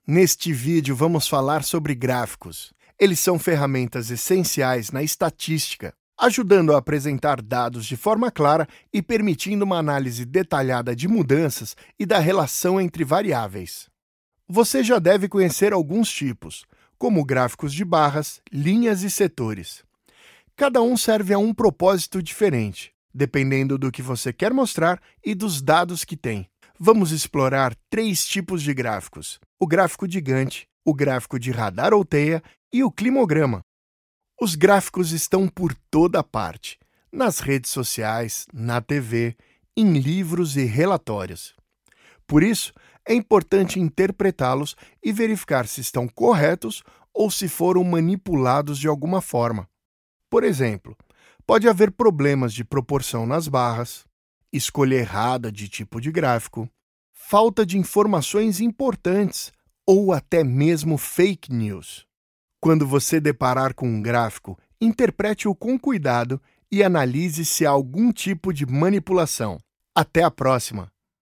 Vídeos Explicativos
Tenho um home studio de nível profissional.
Jovem adulto